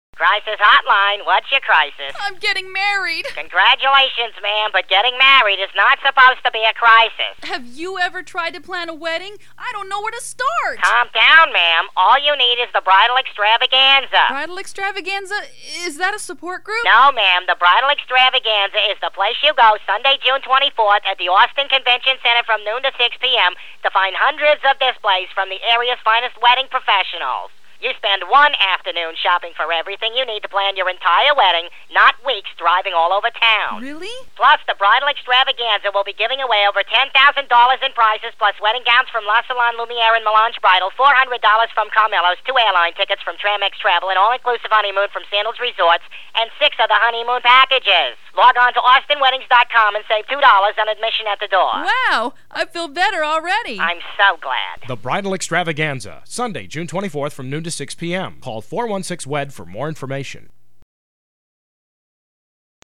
Voiceover Samples
Crisis-Hotline-Wedding-Guide-Spot.mp3